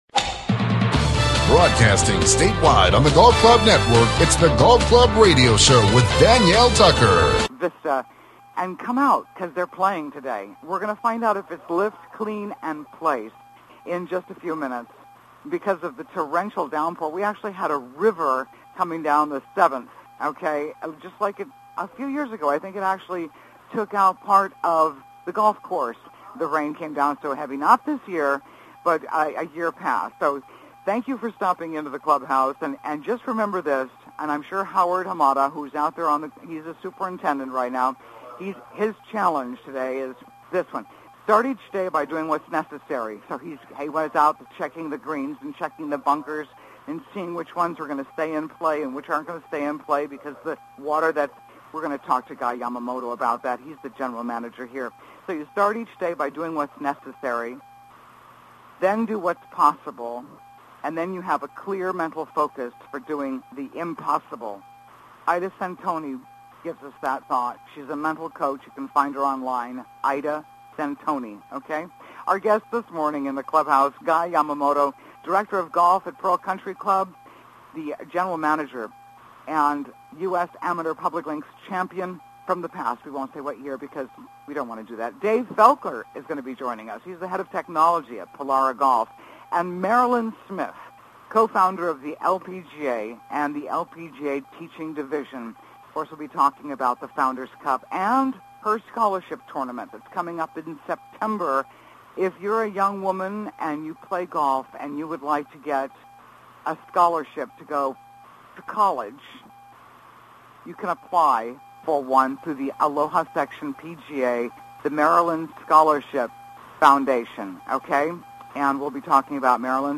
In the Clubhouse: Live Remote from The Pearl Country Club